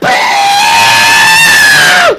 Sound Effects
VSG Doppler Bird Scream